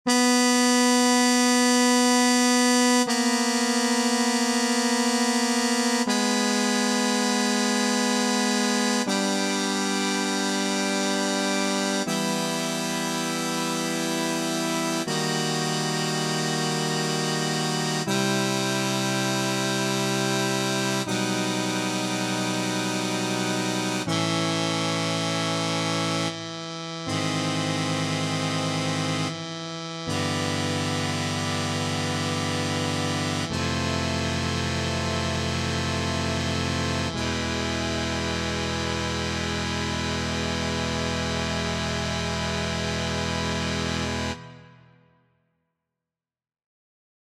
Key written in: E Minor
How many parts: 4
Type: Other male
All Parts mix: